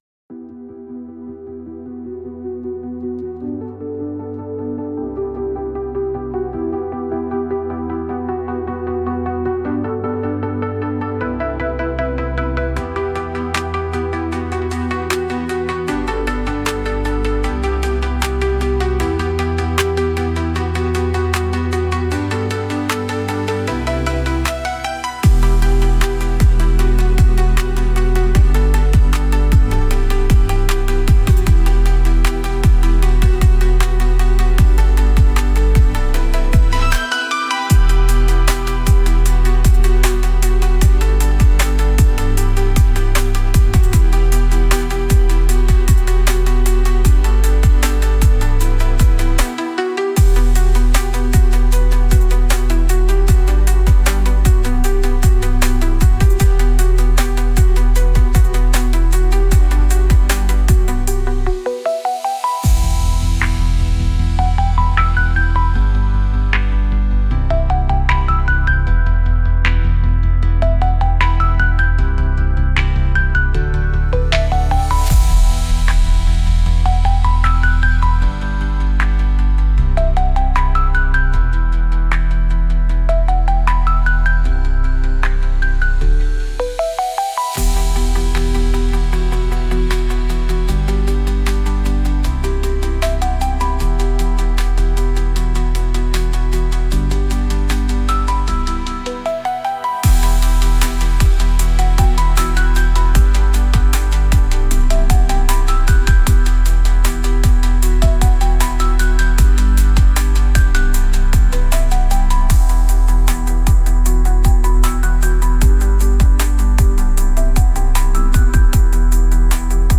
Instrumental - Real Liberty Media DOT xyz 3.51